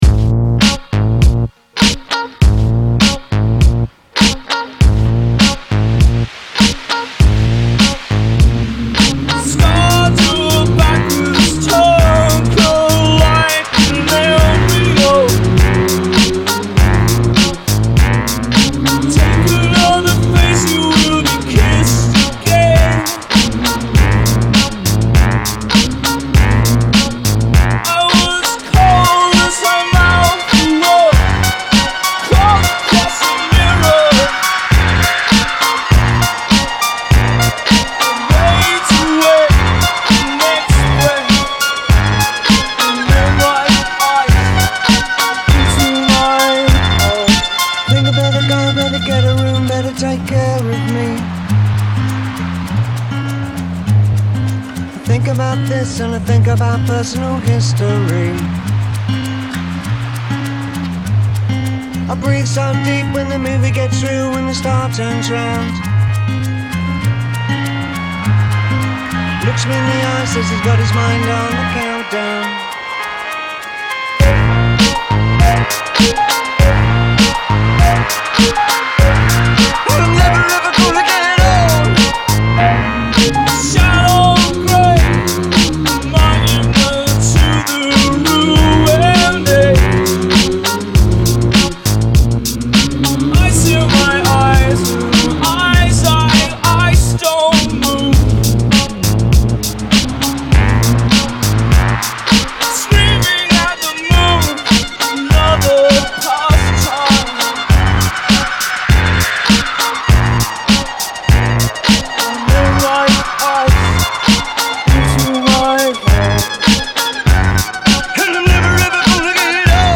all mashups